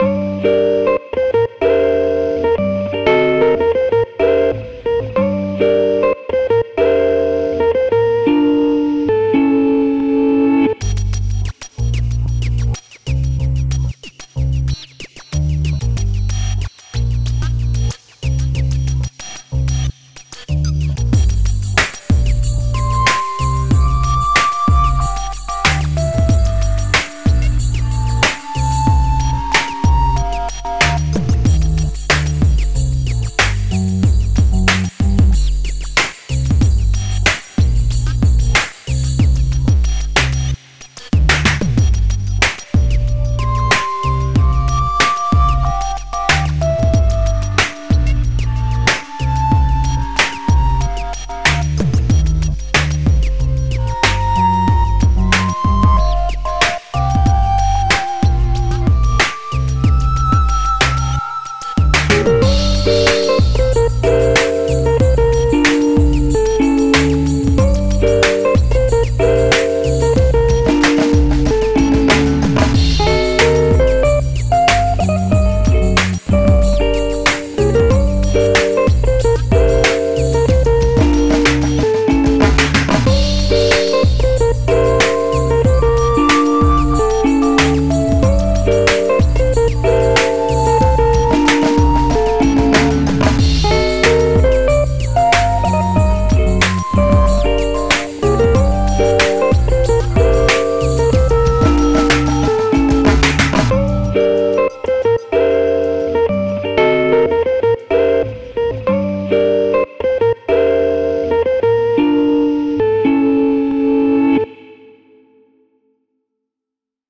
Hip Hop Battle #42 Whatever The Weather.
Lowpassed with some sample rate reduction.
• Kick and Snare are 808 emulations
• Sci-Fi Hats were my one synth, a stack of three ring-mod oscillators, with tweaking for open hats and chirps
Another music jam group I’m in had a “lo-fi winter” theme at the same time as this battle, so I had some additional tweaks post-battle, including recording the second version direct from boombox speakers with an SM58.